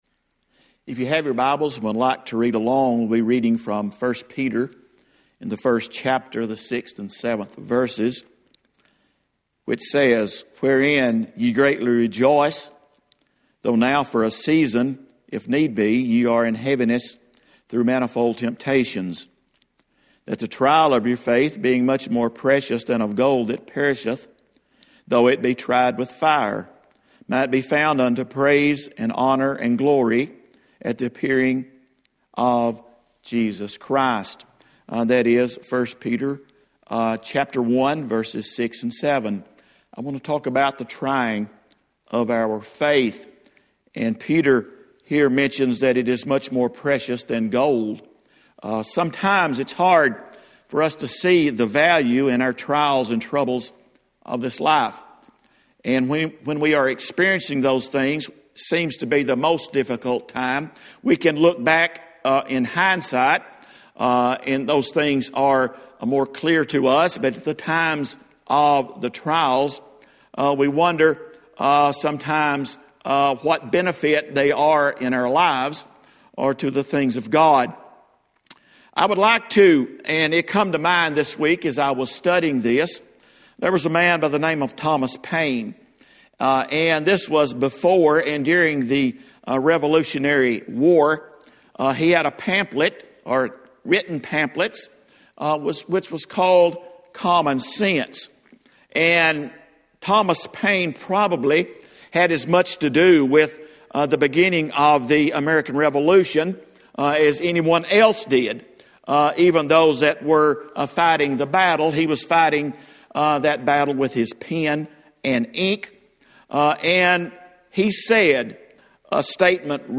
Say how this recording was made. Sunday morning sermon for 2020-03-29 at Old Union Missionary Baptist Church.